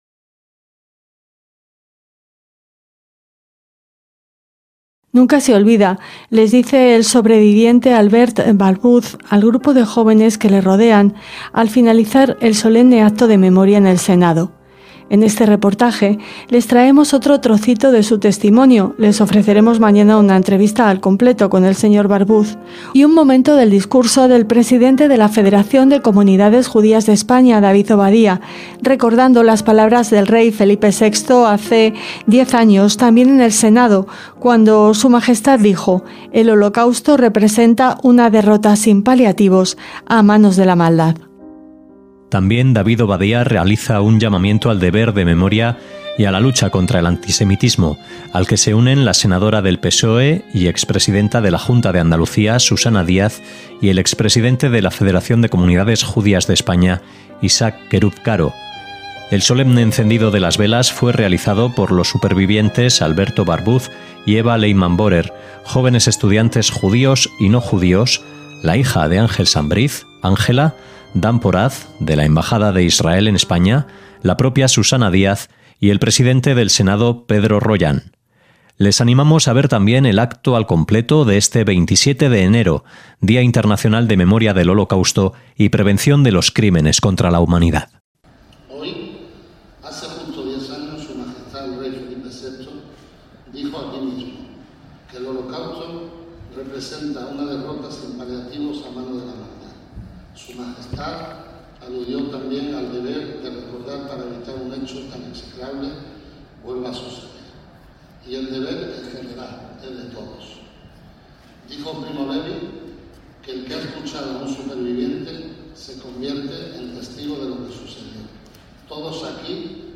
EL REPORTAJE